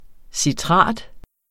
Udtale [ siˈtʁɑˀd ]